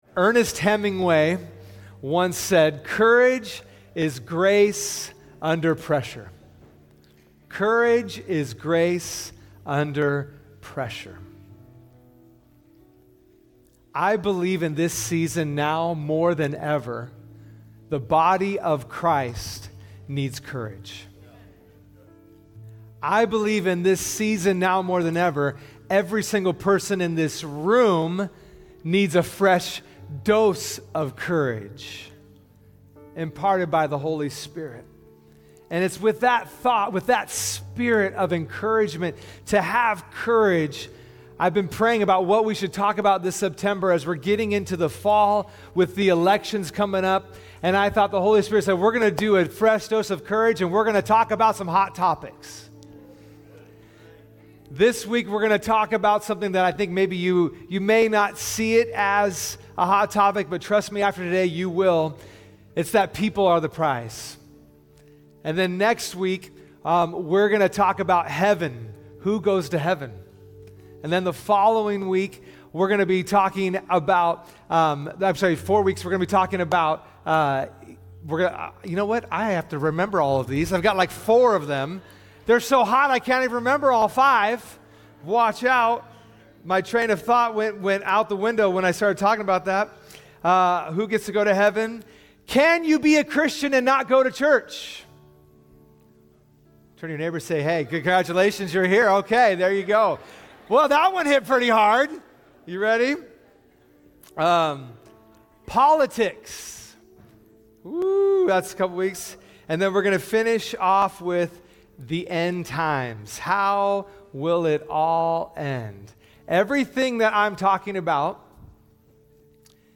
Sunday Messages from Portland Christian Center "Are People the Prize?"